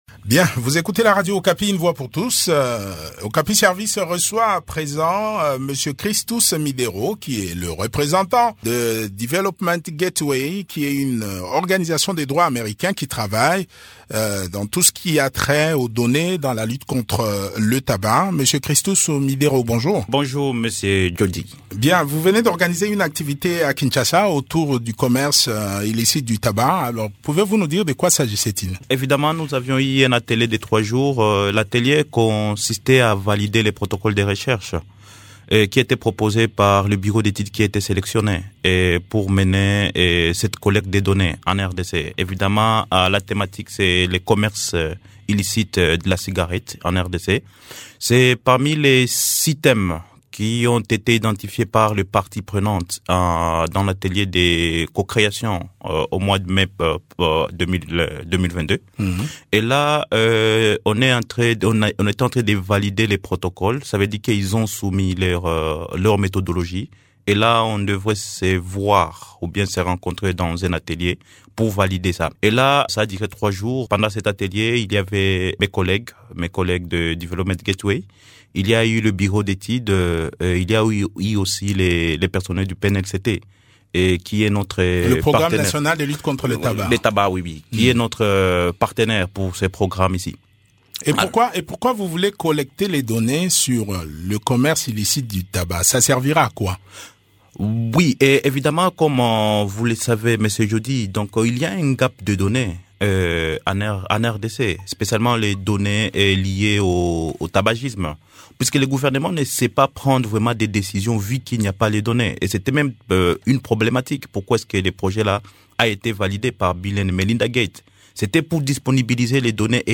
Les détails dans cet entretien